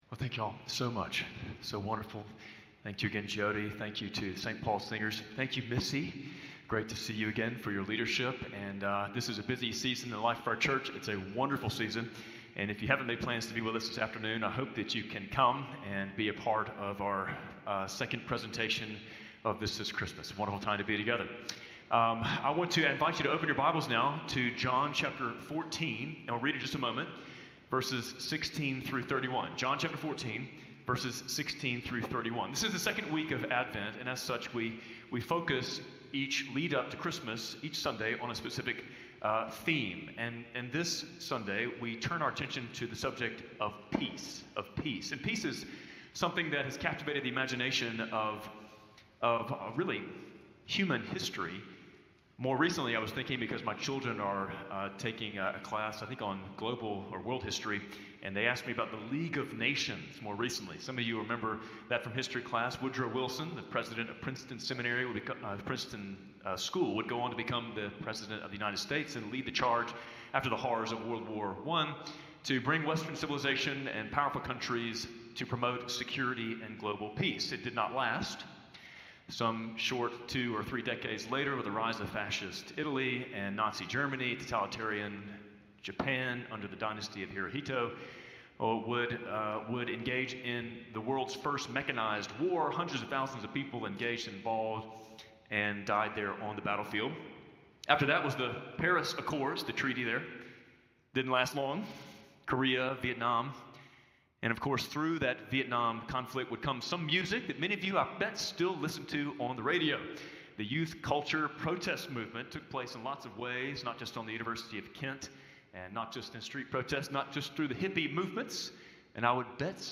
Spring Hill Baptist Sunday Sermons (Audio) / Not as the World Gives